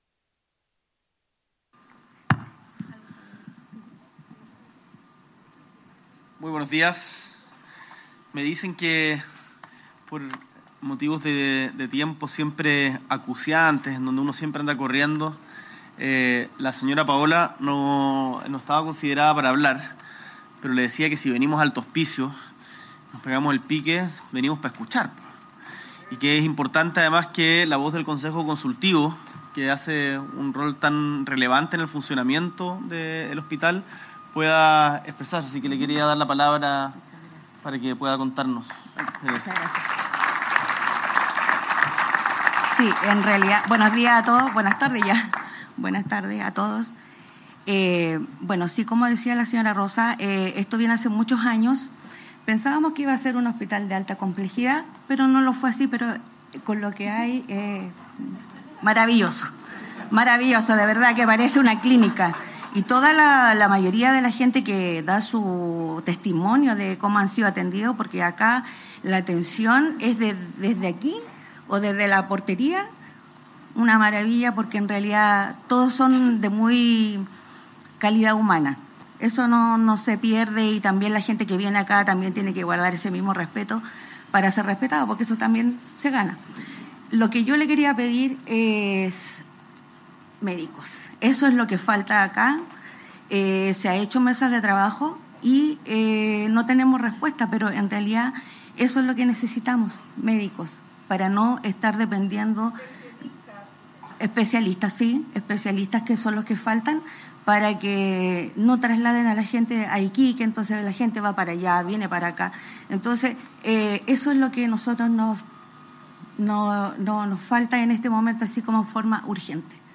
S.E. el Presidente de la República, Gabriel Boric Font, inaugura el Hospital de Alto Hospicio.
Discurso